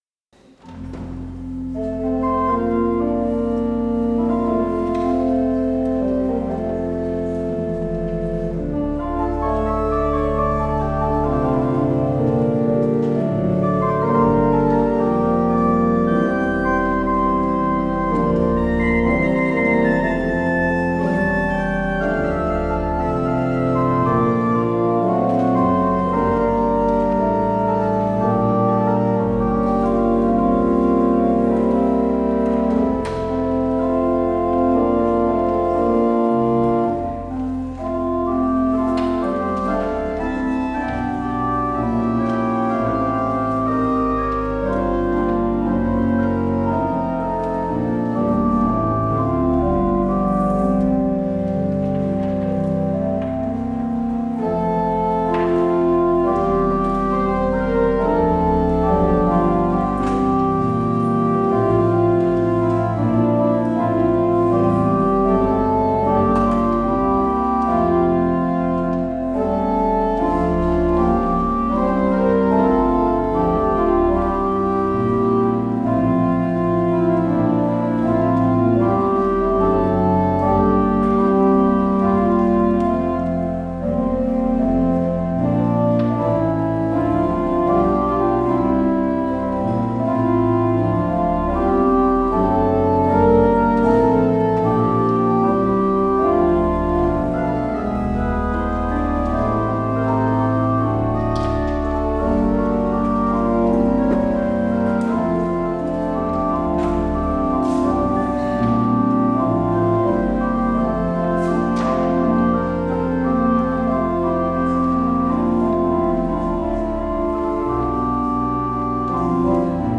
 Beluister deze kerkdienst hier: Alle-Dag-Kerk 25 februari 2026 Alle-Dag-Kerk https